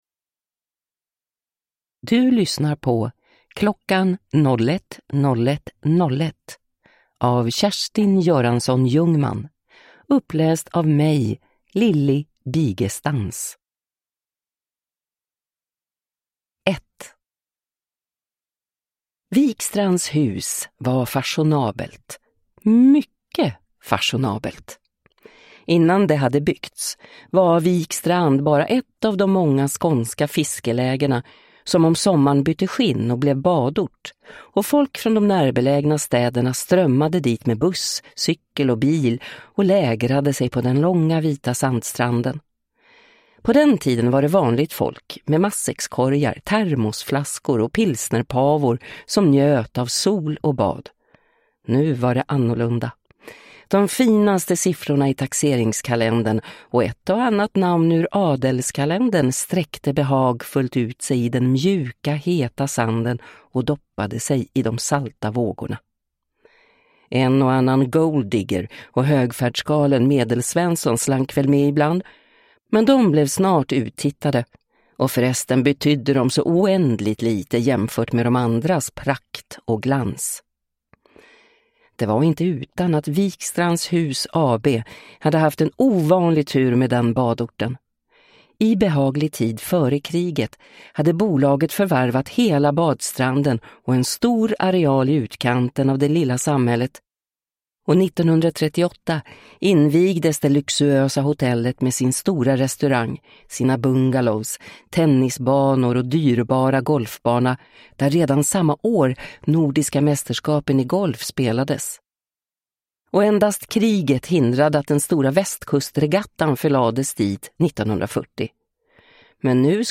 Kl. 01. 01. 01. (ljudbok) av Kjerstin Göransson-Ljungman